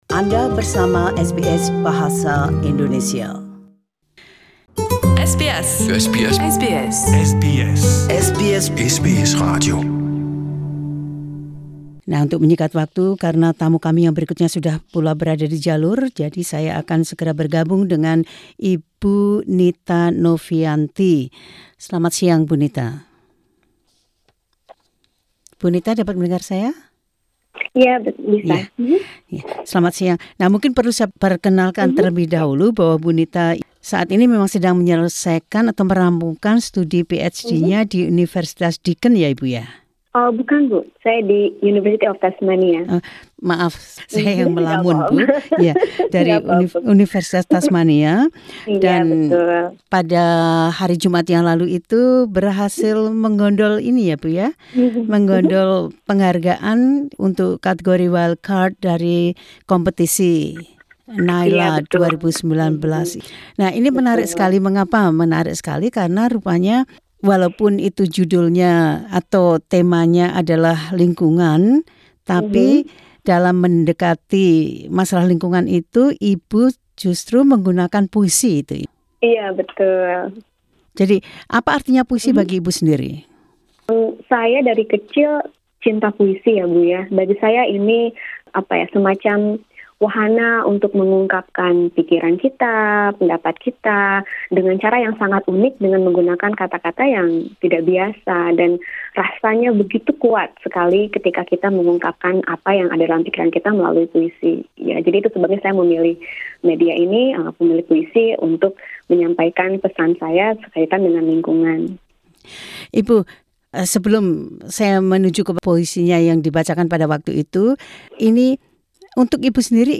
Mahasiswa Indonesia mendeklamasikan 'My Country' karya Dorothea Mackellar dalam bahasa Indonesia.